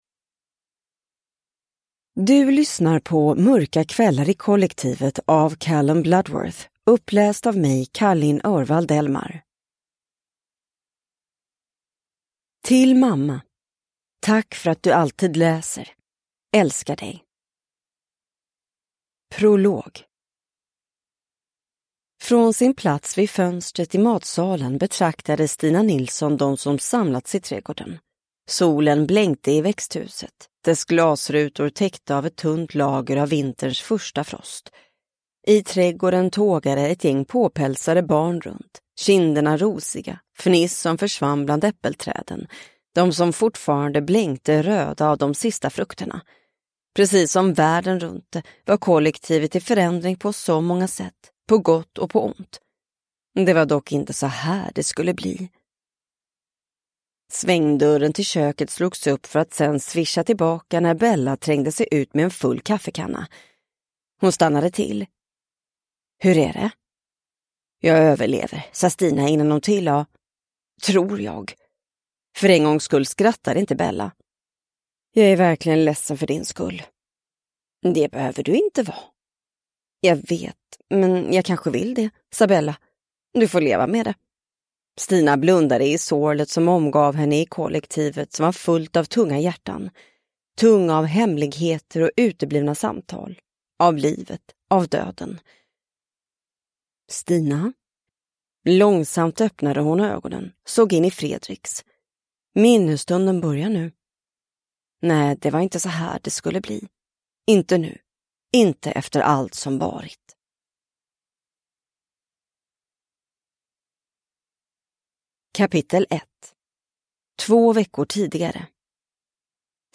Mörka kvällar i Kollektivet (ljudbok) av Callum Bloodworth